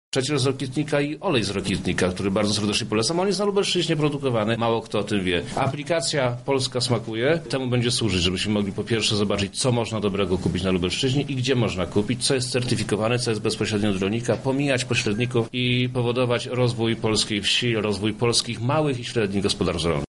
O zaletach inicjatywy opowiada Wojewoda Lubelski Przemysław Czarnek: